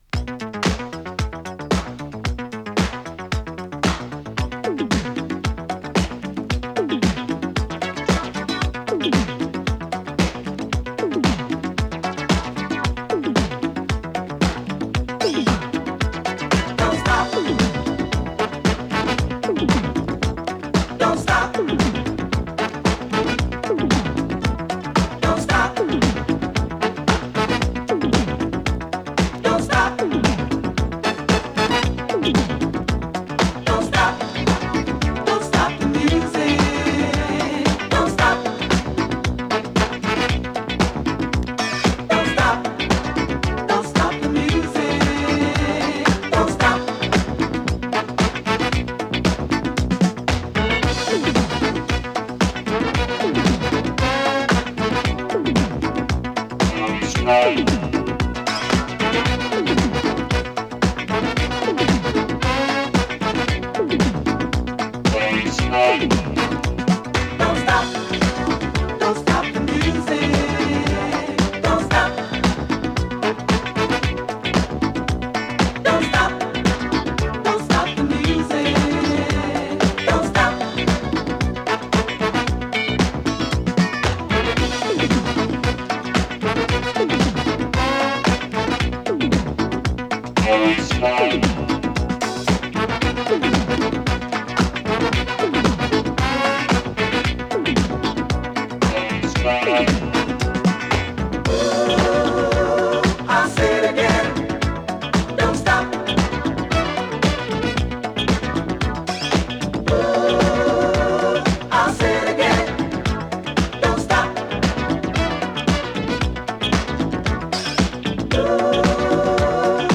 ダッチ ファンク ガラージュ ディスコ
エレクトロと生っぽさが絶妙なガラージュ・クラシック！！